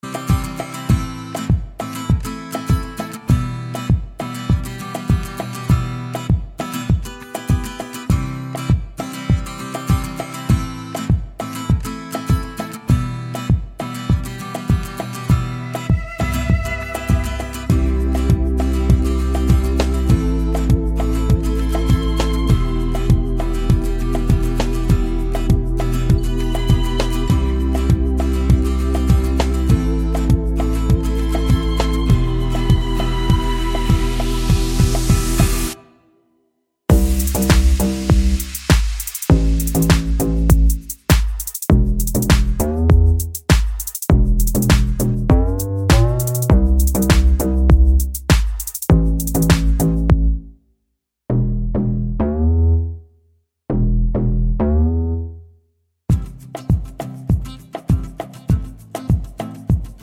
no Backing Vocals Pop (2010s) 3:00 Buy £1.50